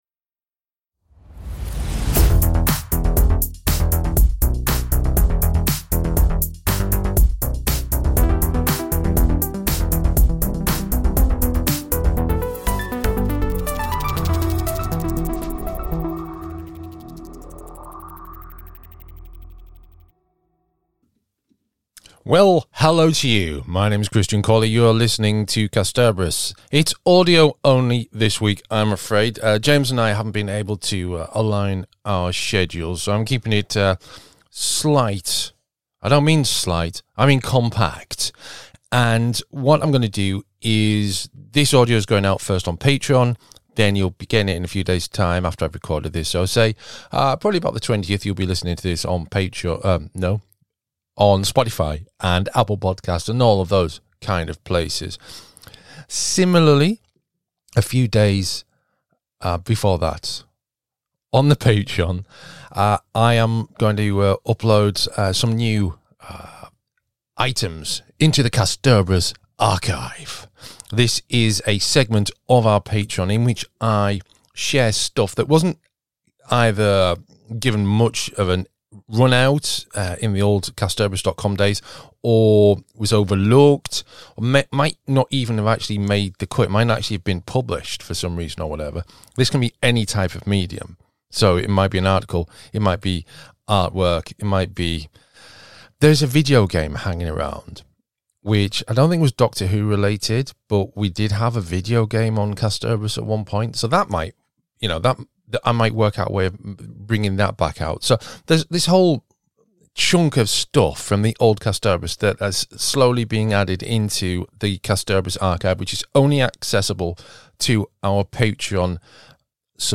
hosting solo